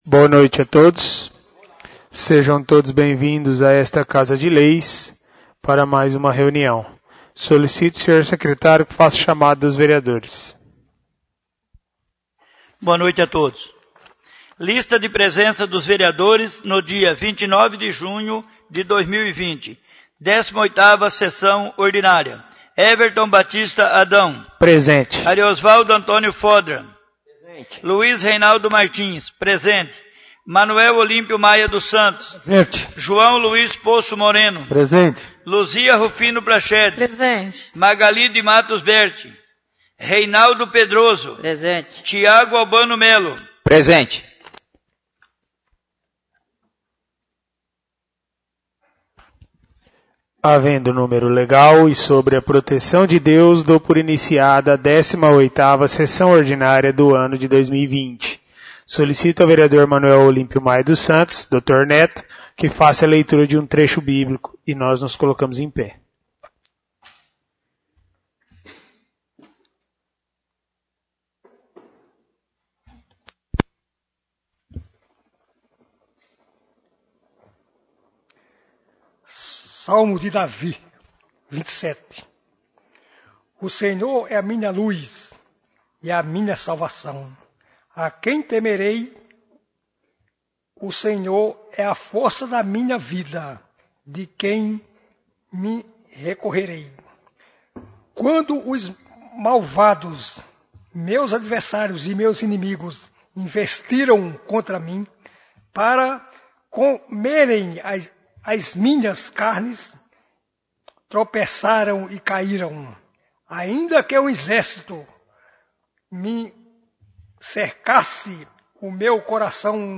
18° Sessão Ordinária